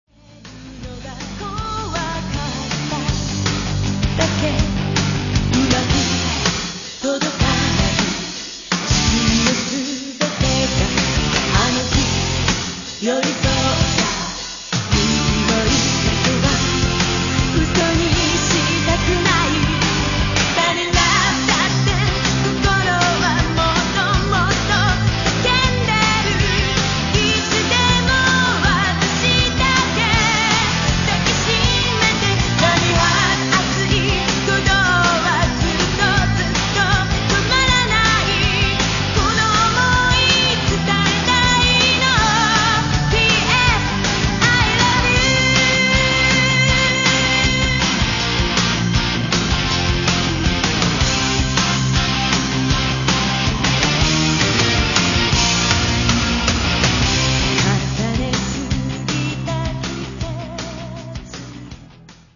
And dig the riff after the end of the chorus
rhythm guitarist